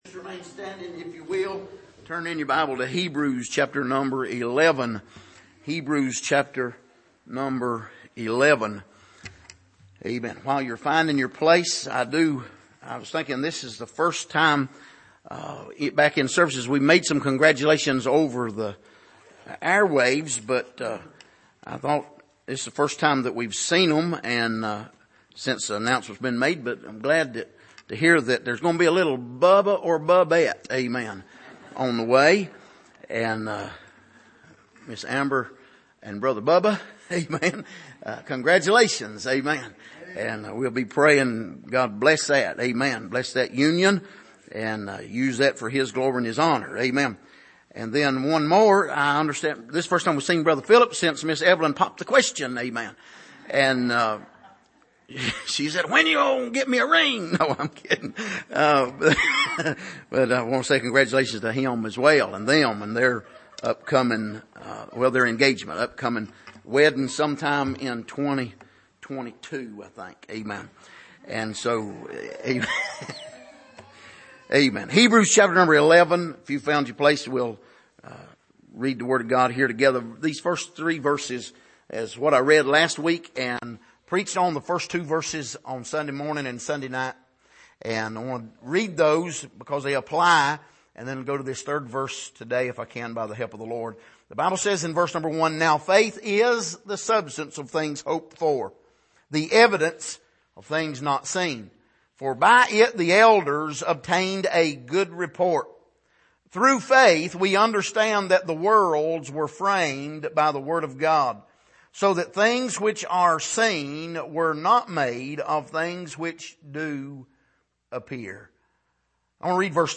Passage: Hebrews 11:1-3 Service: Sunday Morning What is Biblical Faith?